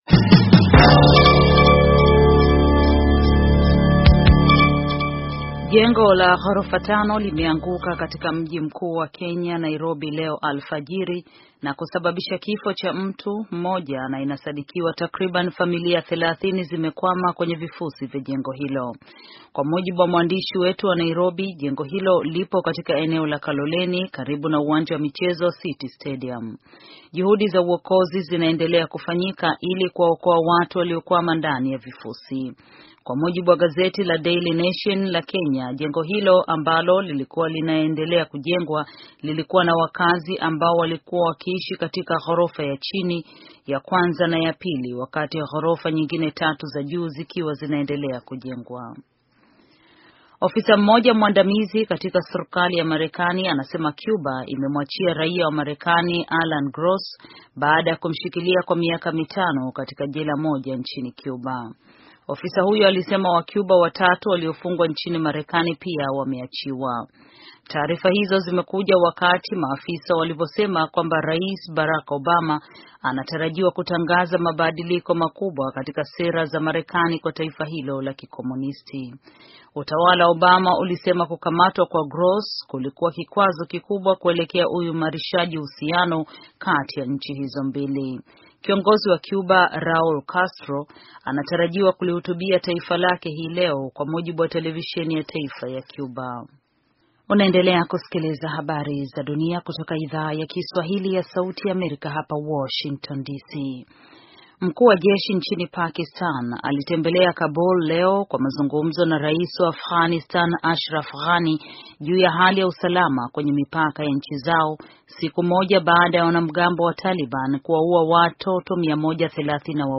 Taarifa ya habari - 6:17